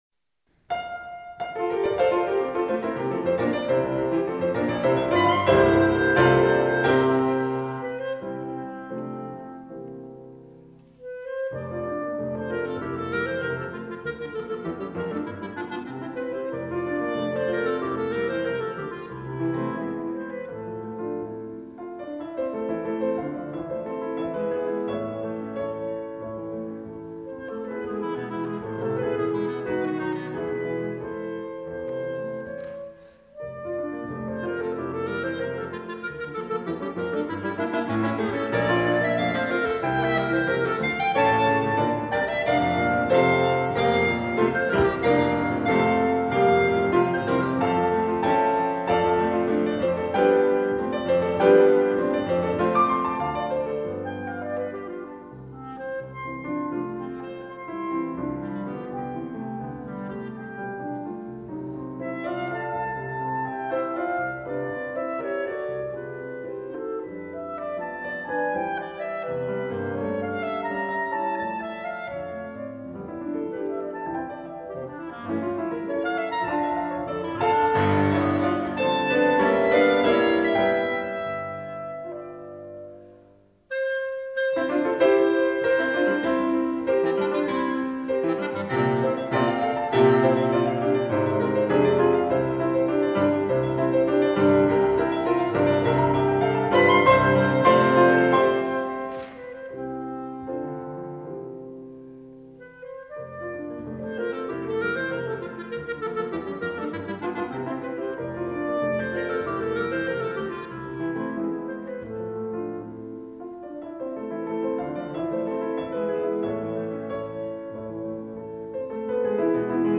Audio (live da Seul)
per pianoforte e clarinetto
Live, il 13 maggio 2001